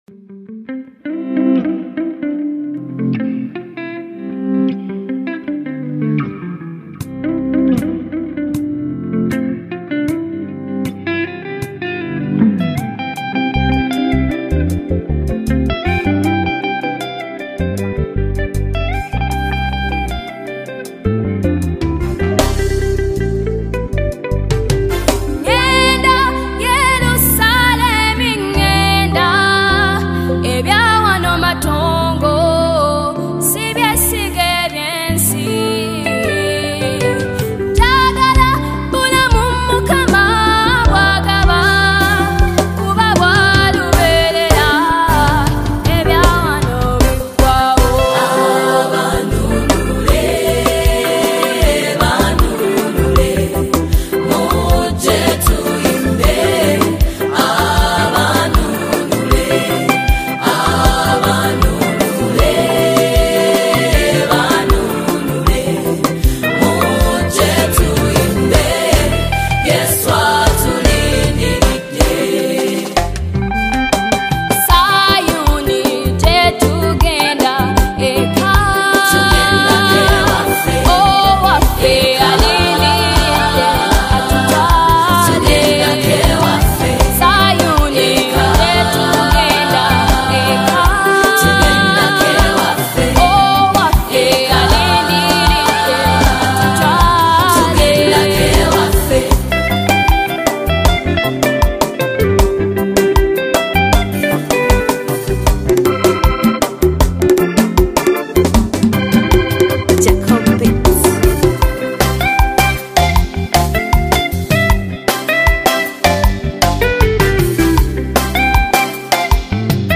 PROPHETIC WORSHIP DECLARATION | 2025 UGANDA GOSPEL
HEAVENLY WORSHIP ANTHEM
📖 CATEGORY: WORSHIP / ZION-THEMED